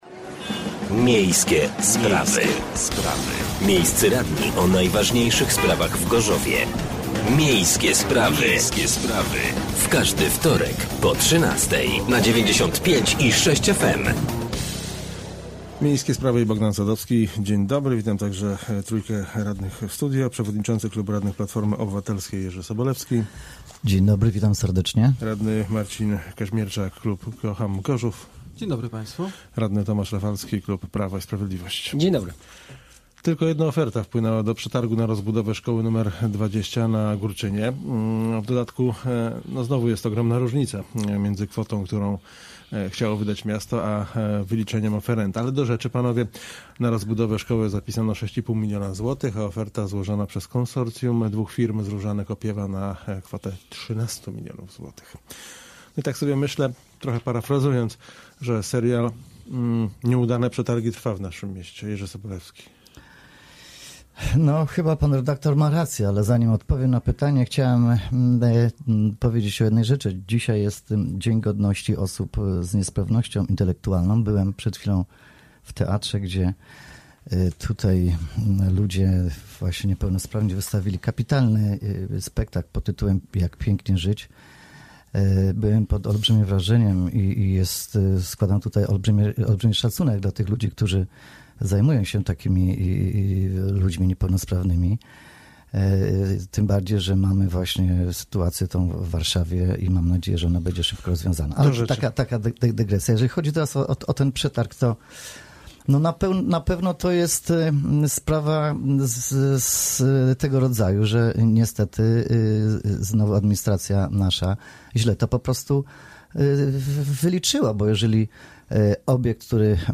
Gośćmi byli radni: Tomasz Rafalski (Prawo i Sprawiedliwość) , Jerzy Sobolewski (Platforma Obywatelska) i Marcin Kazimierczak (Kocham Gorzów)